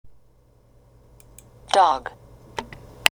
だから、dog を発音する時は最後の「グ」を言いかけたところで首を絞められたように止めなければなりません。
dog（リスニング用音声）
01.dog_.mp3